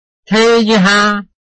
臺灣客語拼音學習網-客語聽讀拼-饒平腔-單韻母
拼音查詢：【饒平腔】te ~請點選不同聲調拼音聽聽看!(例字漢字部分屬參考性質)